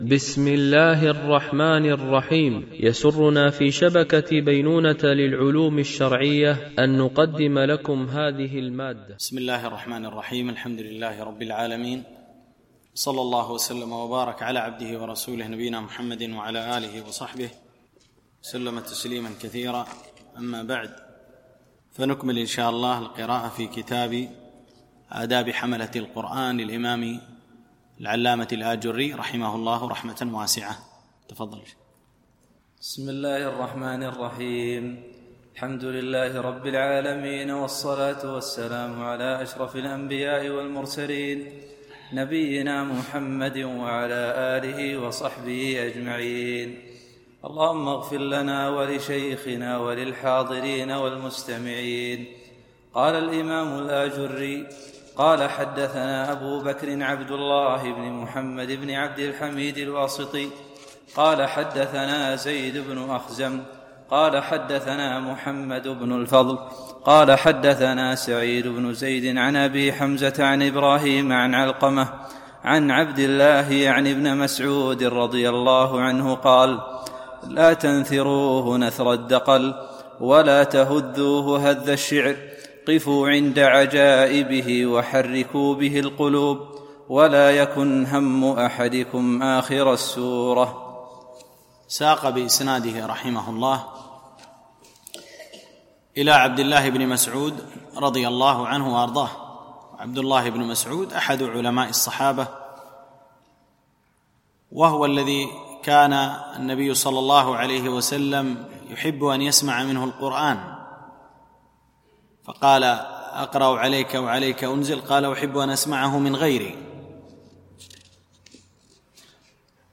شرح آداب حملة القرآن ـ الدرس 2